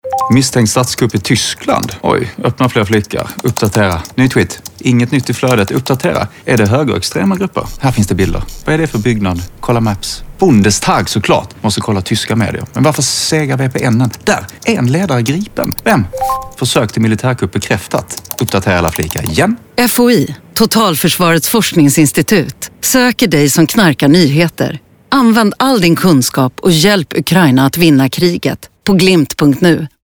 Radioreklam till nyhetsknarkare, mp3